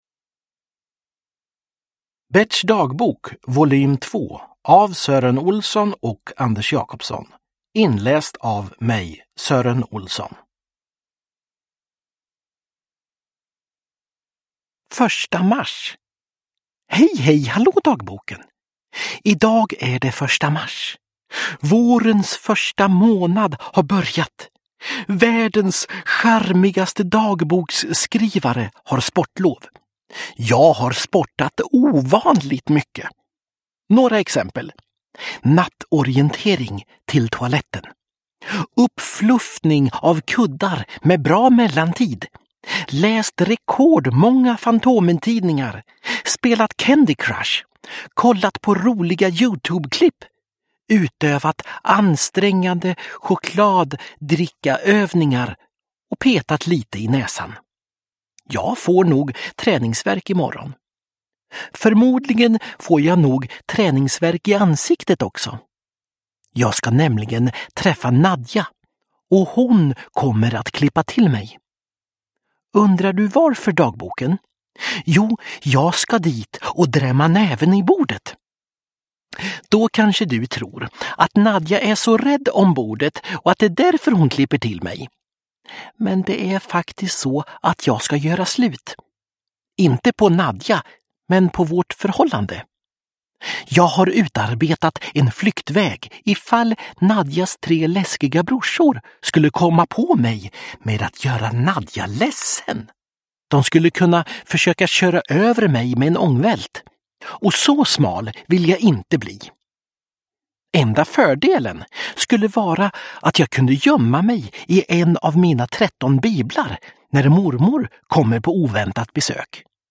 Berts dagbok 2 – Ljudbok – Laddas ner
Uppläsare: Sören Olsson